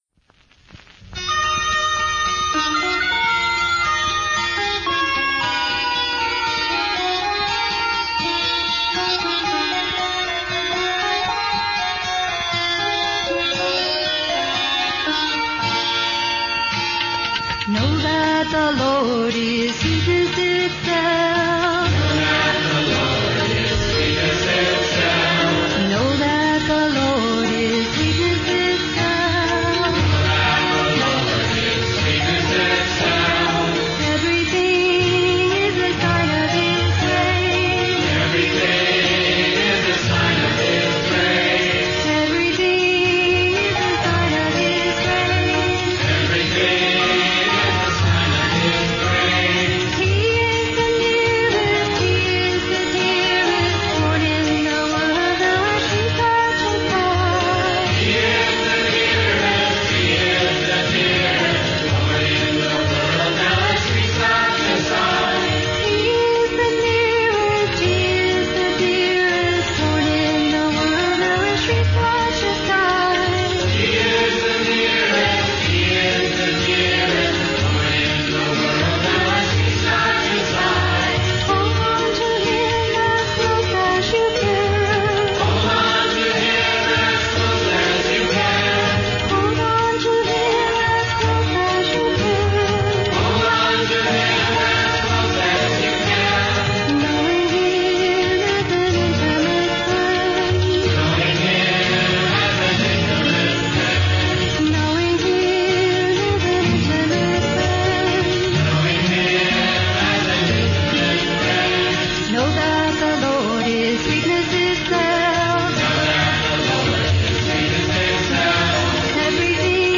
Minor (Natabhairavi)
8 Beat / Keherwa / Adi
4 Pancham / F
2 Pancham / D